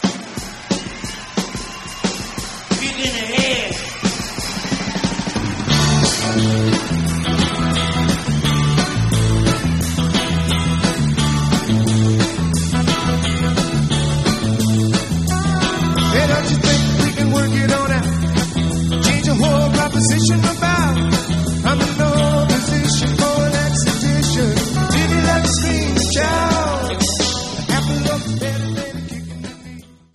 Remastered from original tapes
Americana
Country
Jamband
Psychedelic
Rock
Roots